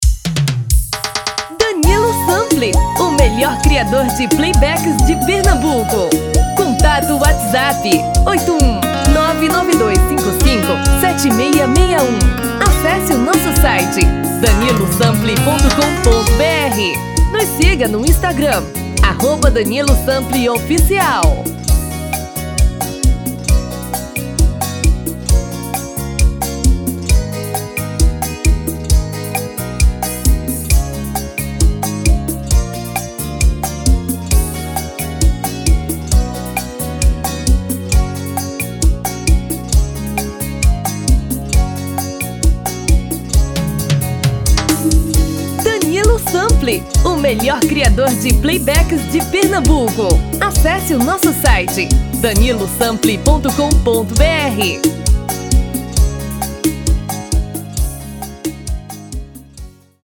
TIPO: Pot-Pourri de 4 músicas sequenciadas
RITMO: Arrocha / Seresta
TOM: Feminino (Original)